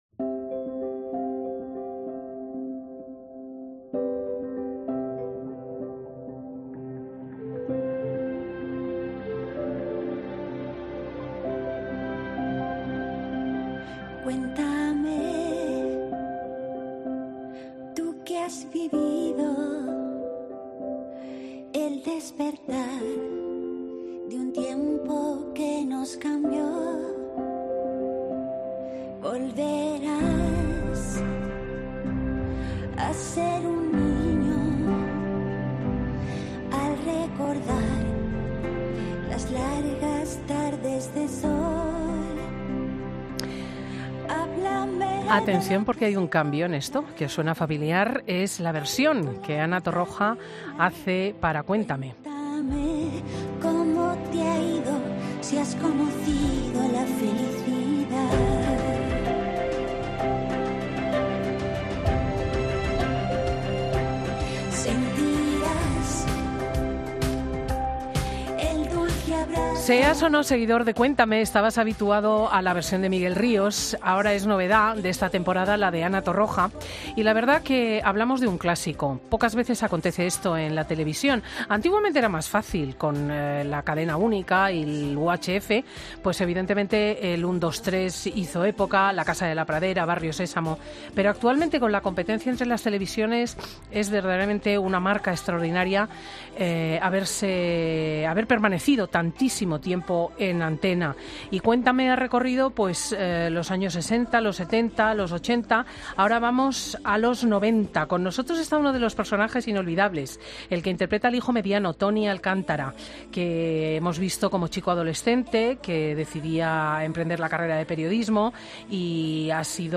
El actor ha pasado por 'Fin de Semana' para presentar la nueva temporada de la serie de TVE